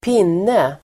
Uttal: [²p'in:e]